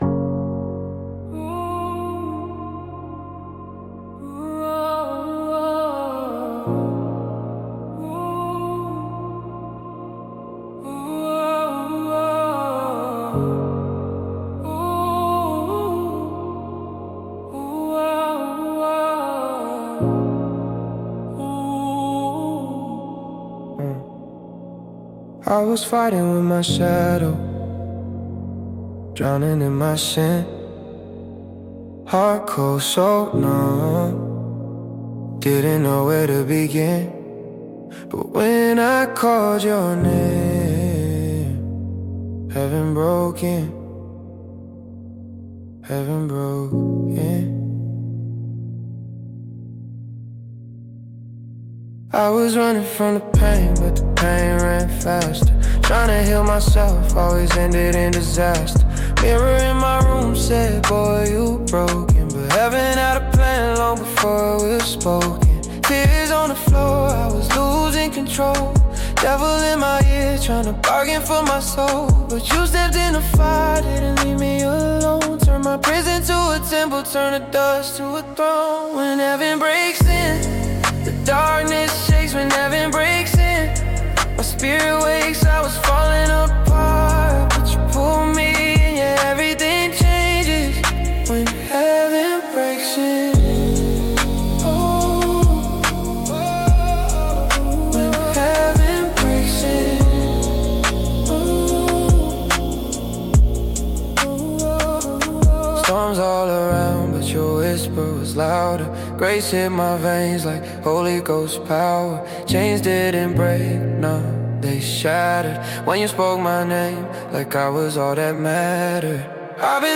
When Heaven Breaks In - Powerful Gospel